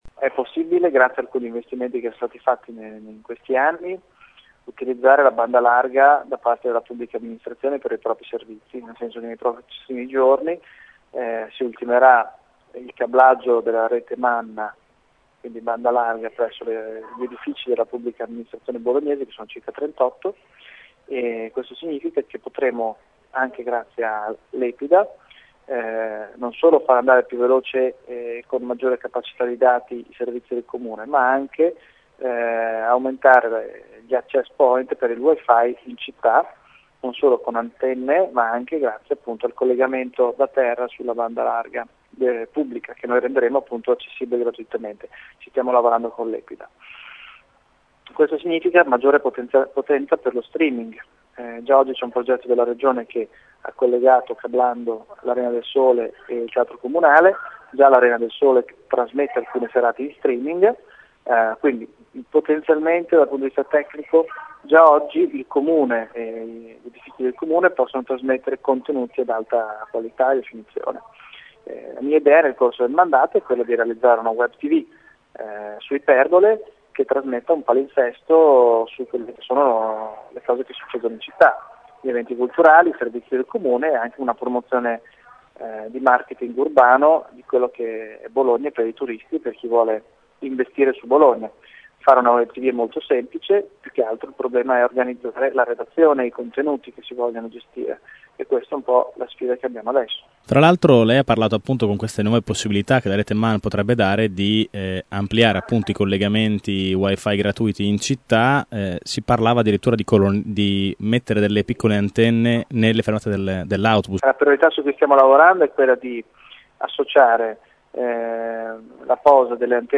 Lo ha detto l’assessore al marketing urbano del Comune di Bologna Matteo Lepore che questa mattina è intervenuto alla seduta della commissione istruzione cultura giovani e comunicazione dedicata al tema del wi-fi gratuito.